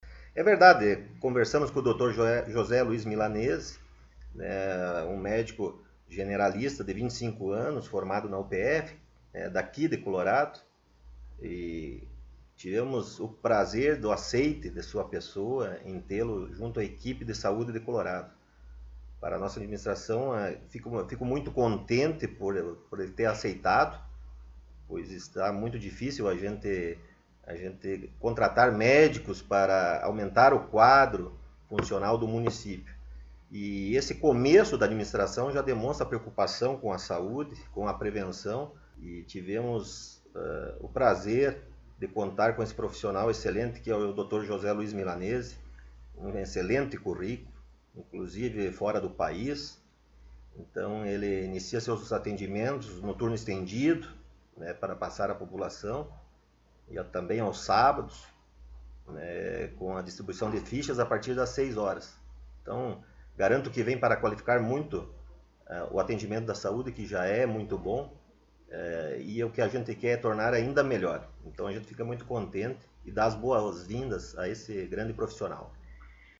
Prefeito Municipal Rodrigo Sartori concedeu entrevista
O Colorado em Foco esteve entrevistando na última sexta-feira ( 17 ), no gabinete da Prefeitura Municipal o prefeito Rodrigo Sartori.